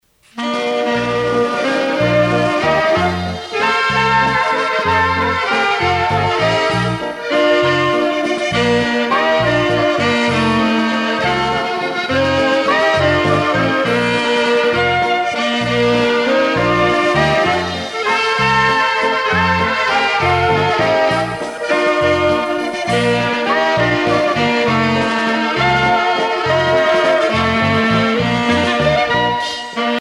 valse musette
Orchestre de variétés
Pièce musicale éditée